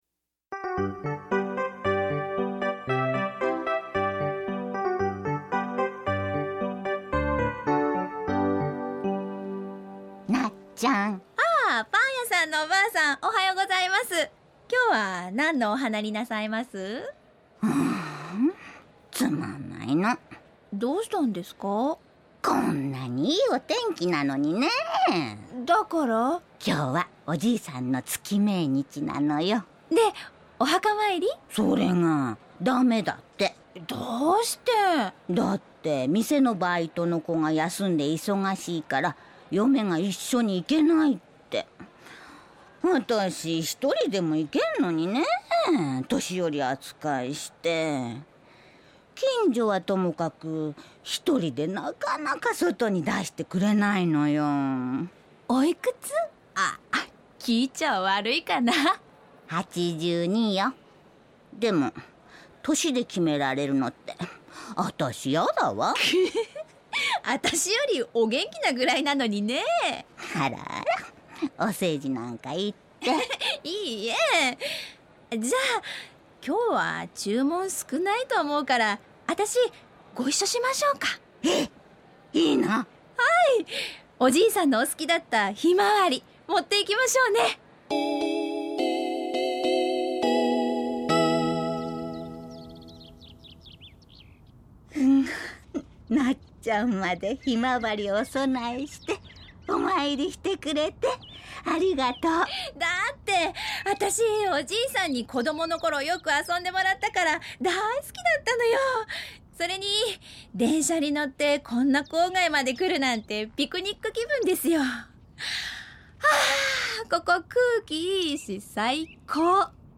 ●ラジオドラマ「花ものがたり」